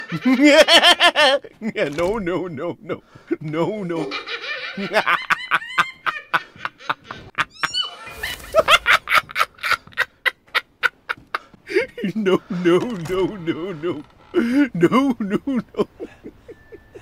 เสียงหัวเราะพี่เอก HRK Hahaha… Goodnight and Kiss… เสียงพี่เอก HRK
หมวดหมู่: เสียงมีมไทย
คำอธิบาย: HRK REV EP5 LOL sound effect ประกอบไปด้วยเสียงหัวเราะที่ตลกขบขันและเสียง no…no…no… ของตัวละครชื่อดัง HEARTROCKER คุณสามารถใช้เอฟเฟกต์เสียงนี้ในการตัดต่อวิดีโอ, พากย์วิดีโอตลก ๆ, หรือตั้งเป็นเสียงเรียกเข้าสำหรับโทรศัพท์ของคุณ
hrk-rev-ep5-lol-sound-effect-th-www_tiengdong_com.mp3